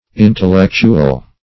Intellectual \In`tel*lec"tu*al\, n.